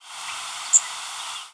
Magnolia Warbler diurnal flight calls
Bird in flight.